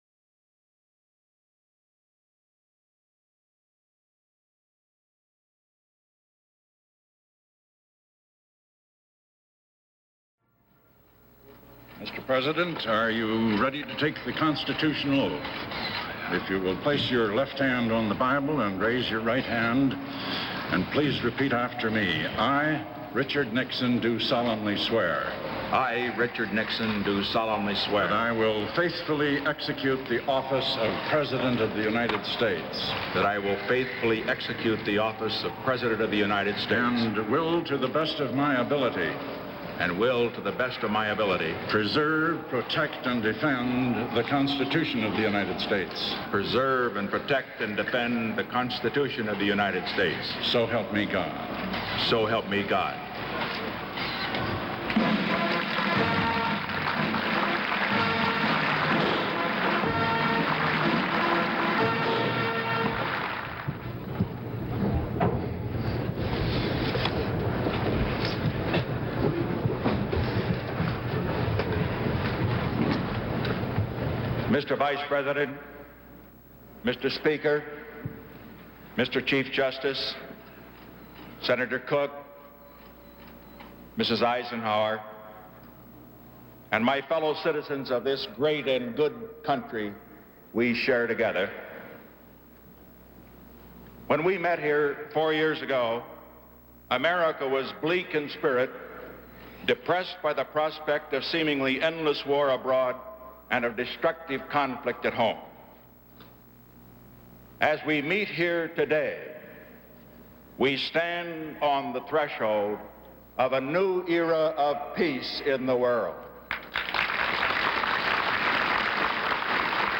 January 20, 1973: Second Inaugural Address